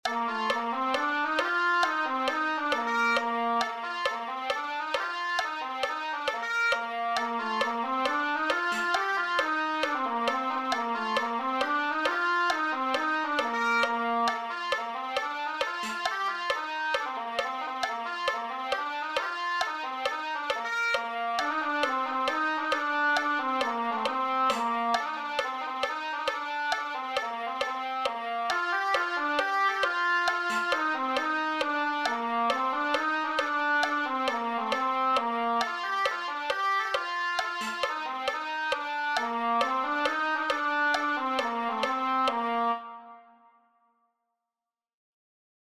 Diverses partitions ensemble batterie ,répertoire bagad , pipe band.
Daou Don Doub ar Menezioù C et B 135 bpm (1).mp3